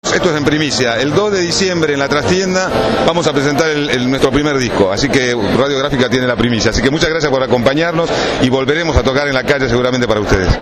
El actor Juan Palomino participó de la convocatoria para conmemorar el sexto aniversario del rechazo al ALCA